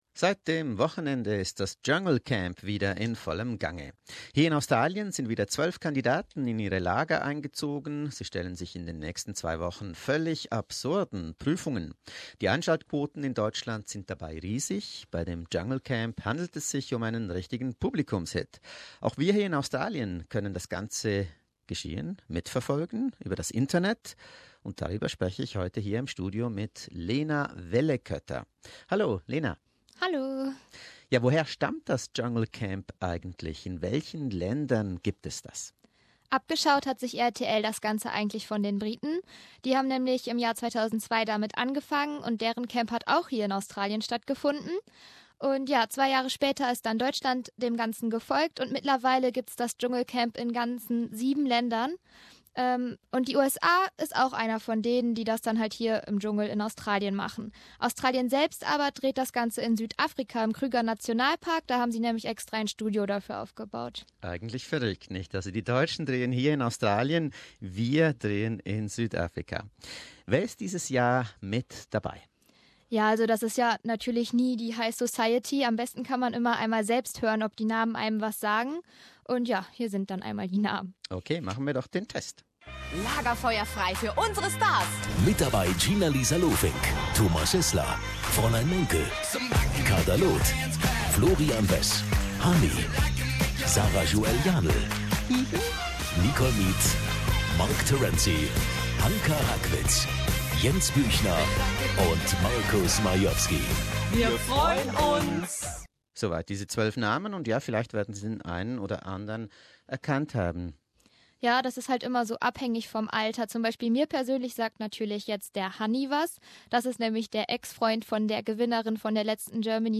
Jungle Camp 2017 - A studio interview
An interview, three days into series 11.